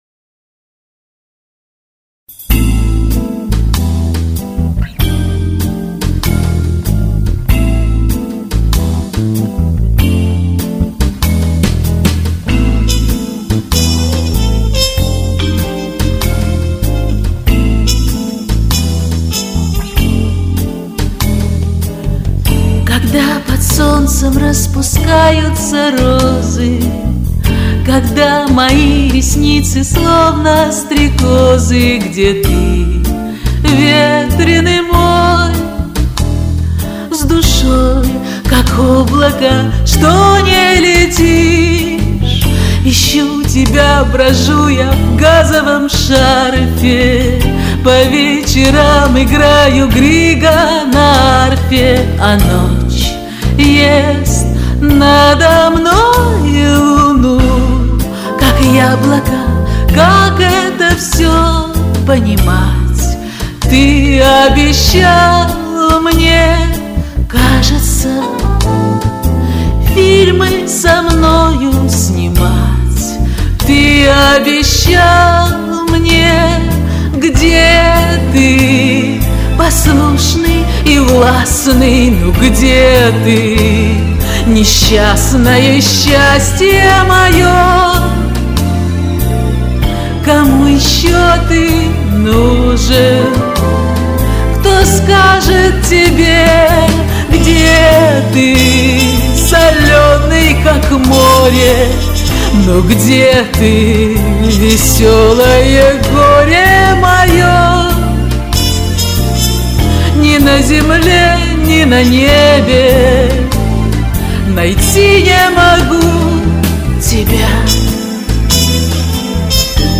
Звук действительно мало изменился, но изменился.